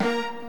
flugelhorn
guitar
Actually, I do have one: the opening horn kick on
(This acts as a great error sound for your PC or Mac.)